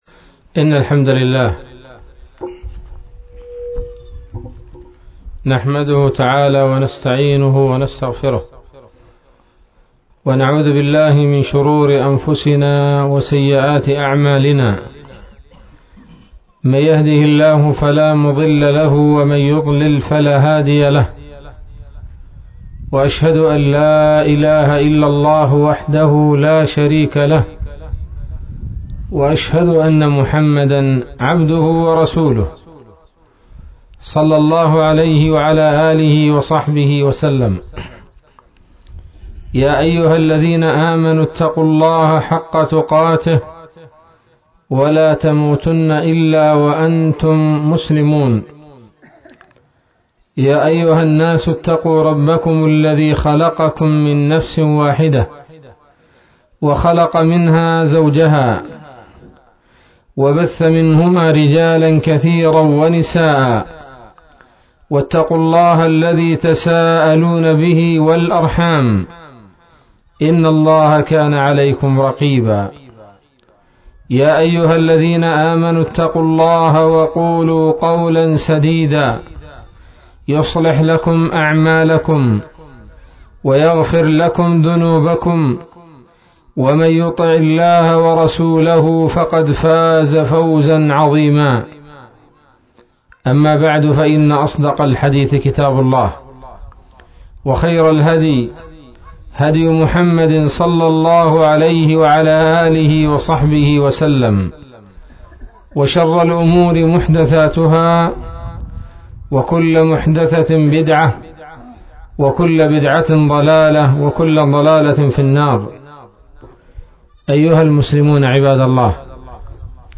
محاضرة بعنوان: ((دعوة النبي صلى الله عليه وسلم)) ليلة السبت 13 ربيع أول 1439هـ